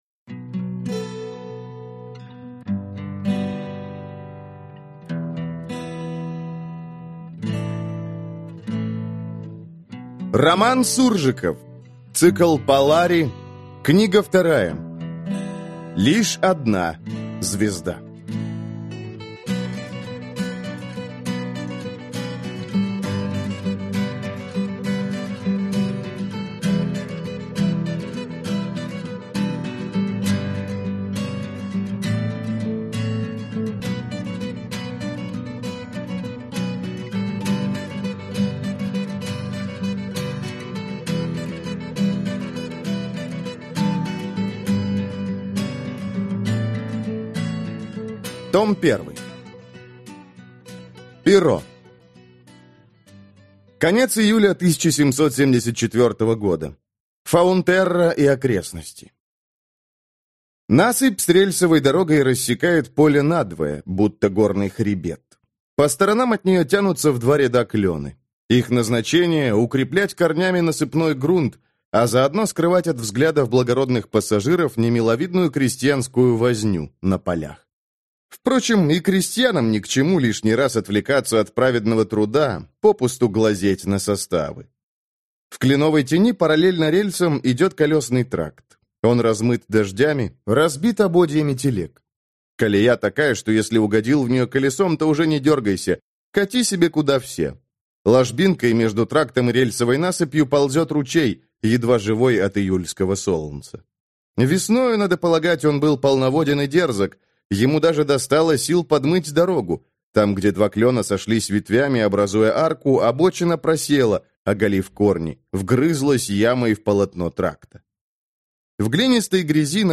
Аудиокнига Лишь одна Звезда. Том 1 | Библиотека аудиокниг